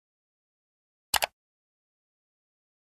Mouse Click (Sound Effect).mp3